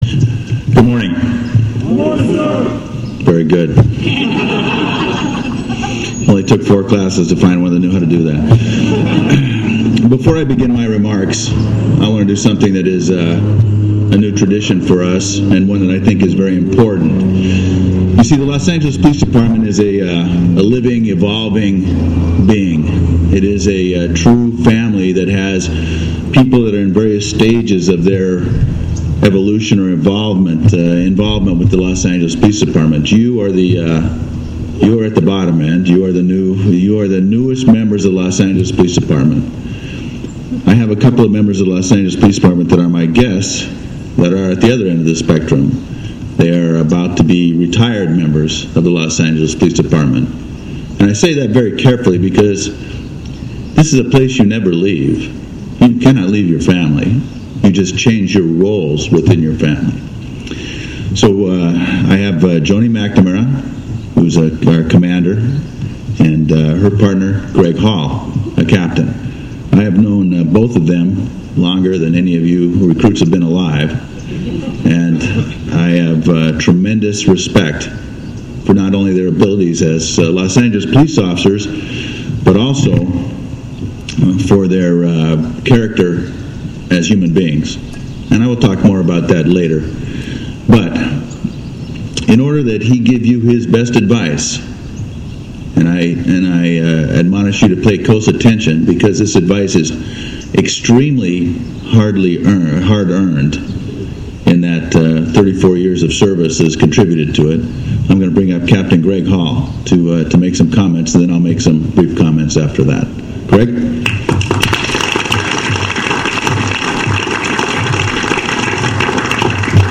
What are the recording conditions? Twenty-one individuals underwent six months of intense training which culminated with today’s ceremony in front of Department brass, dignitaries and their family and friends.